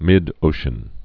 (mĭdōshən)